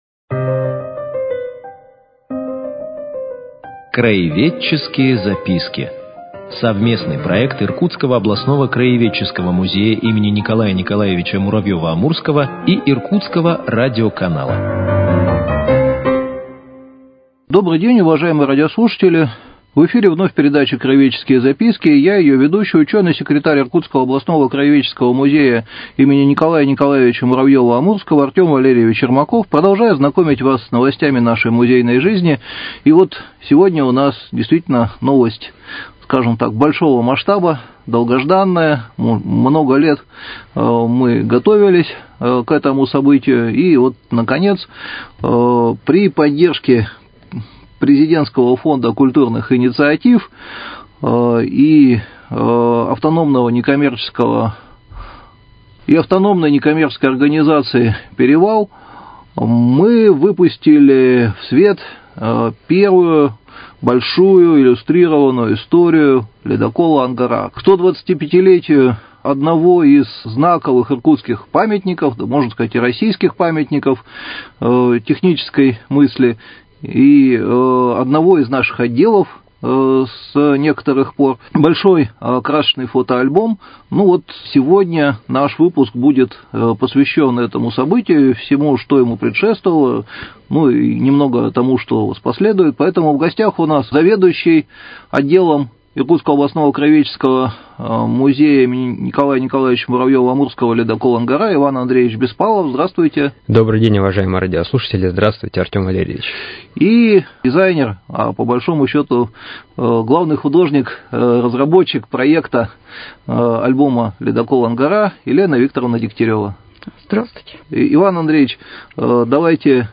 Мы предлагаем вниманию слушателей цикл передач – совместный проект Иркутского радиоканала и Иркутского областного краеведческого музея.